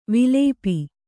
♪ vilēpi